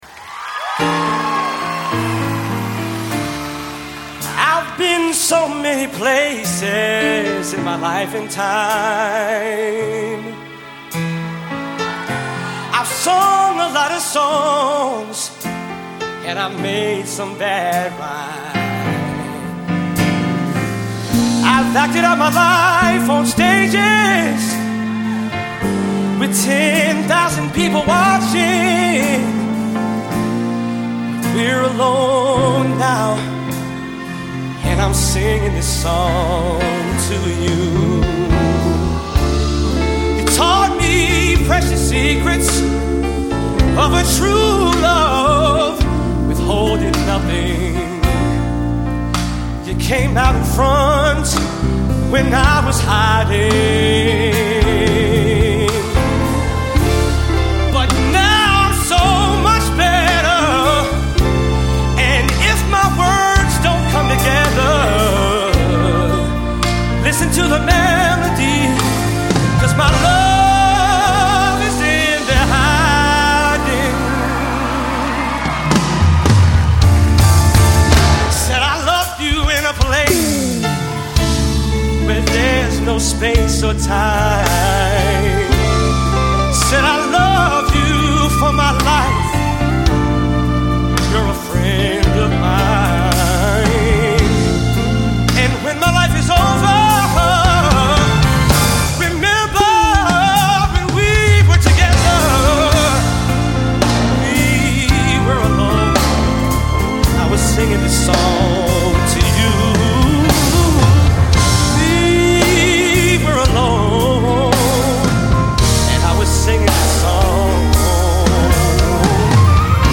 R & B Pop